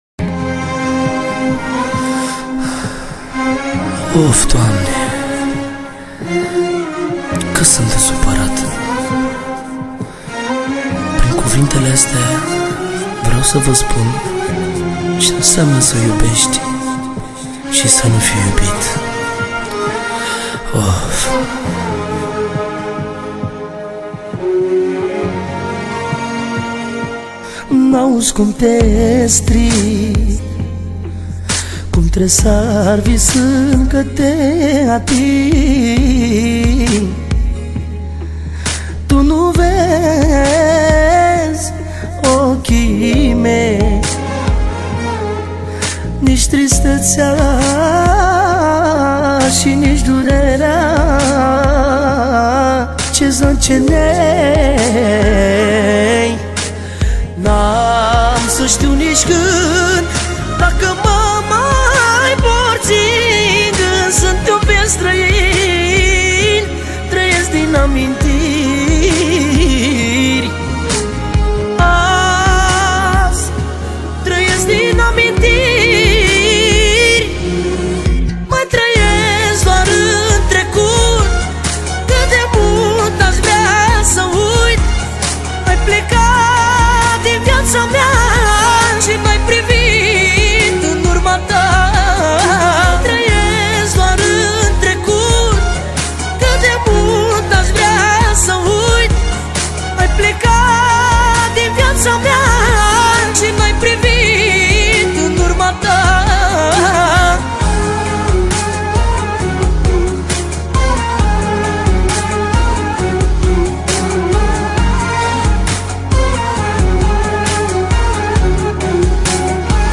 Data: 31.10.2024  Manele New-Live Hits: 0